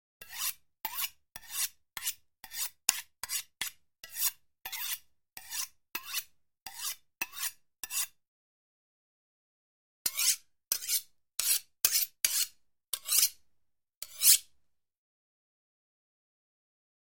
Звуки заточки ножа
На этой странице представлены звуки заточки ножа в разных вариациях: от резких металлических скрежетов до монотонных ритмичных движений.
Заточка ножа и режущий звук